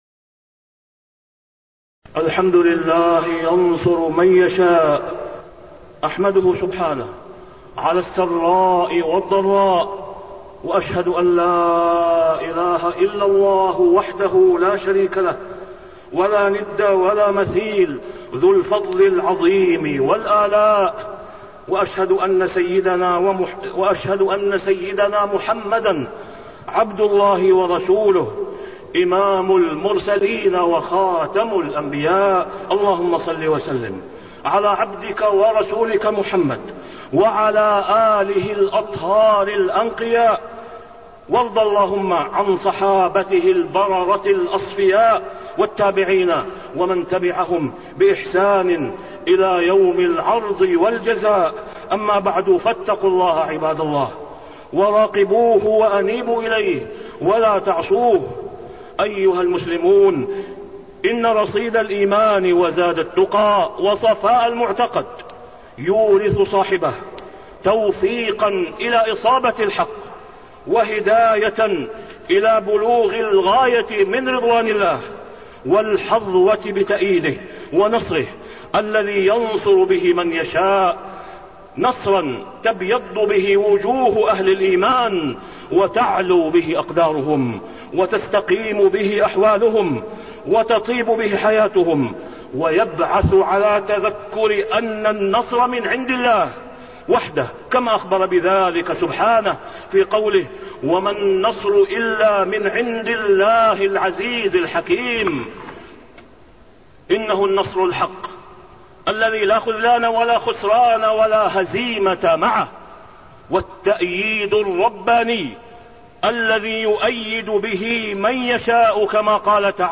تاريخ النشر ١٩ شعبان ١٤٣٤ هـ المكان: المسجد الحرام الشيخ: فضيلة الشيخ د. أسامة بن عبدالله خياط فضيلة الشيخ د. أسامة بن عبدالله خياط أسباب النصر